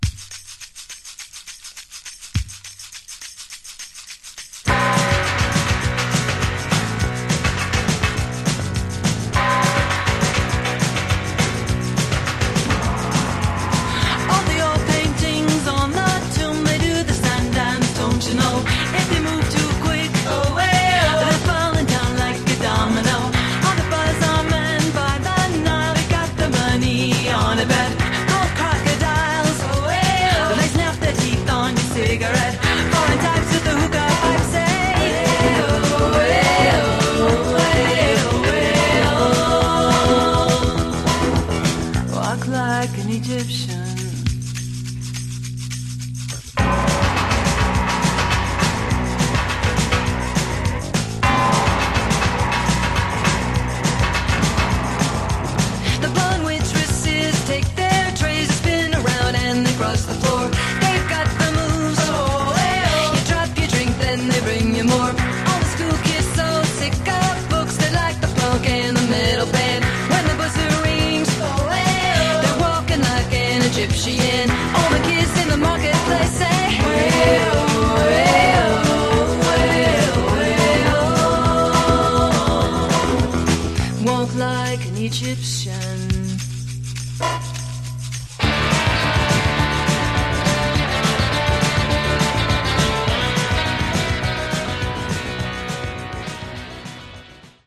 Genre: Power Pop